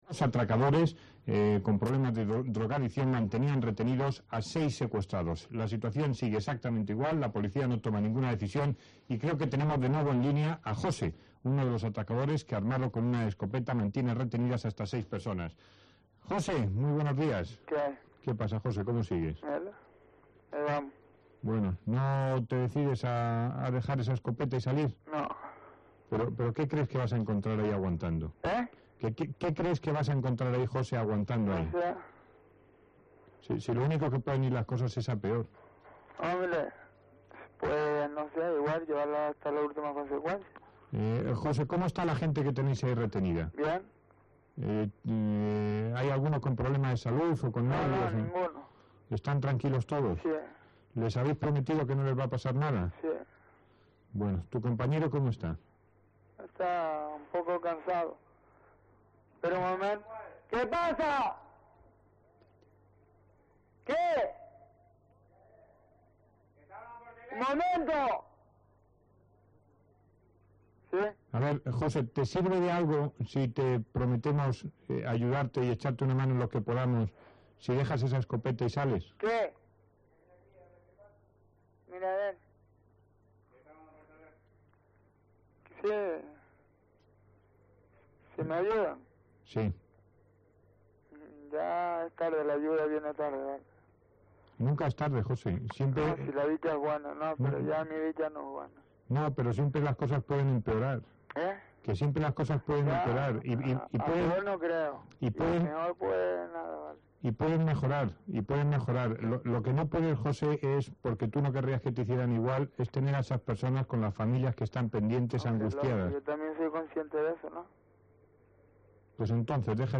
Una de sus entrevistas más humanas e impactantes se produjo el 28 de septiembre de 1995.
Antonio Herrero entrevista a un atracador con rehenes